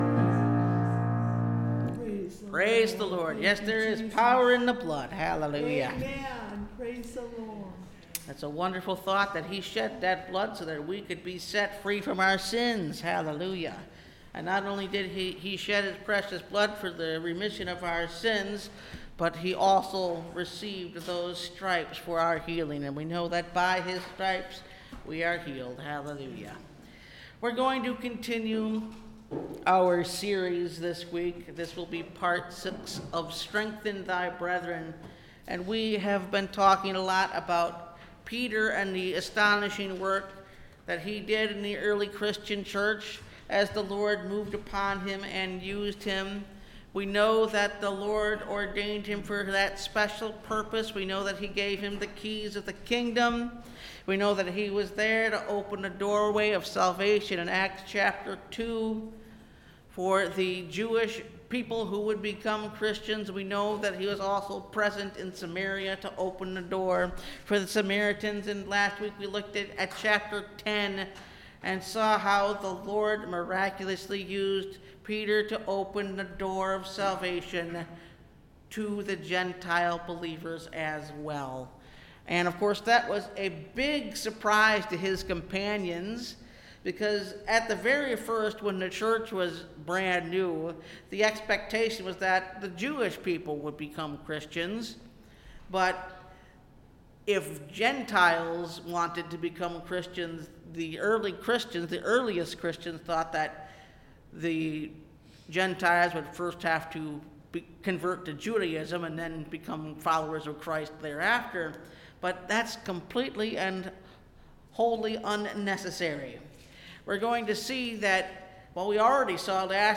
Strengthen Thy Brethren – Part 6 (Message Audio) – Last Trumpet Ministries – Truth Tabernacle – Sermon Library
Service Type: Sunday Morning